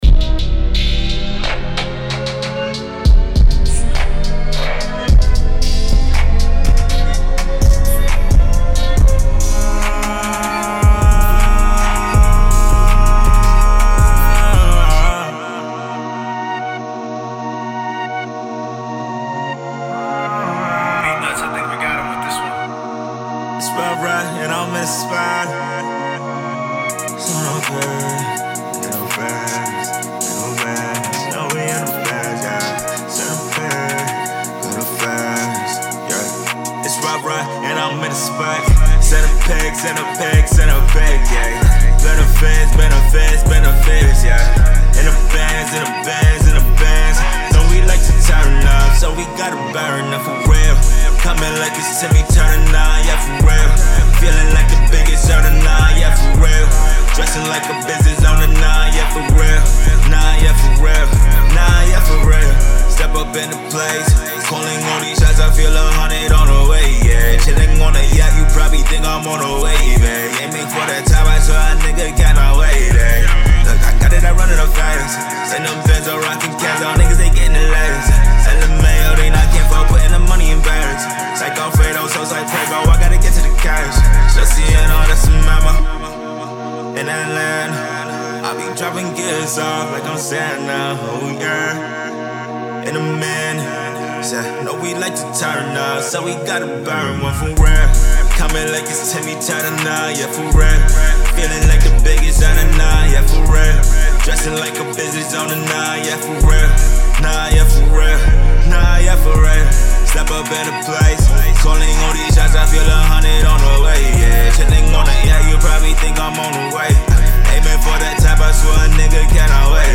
MIXED, MASTERED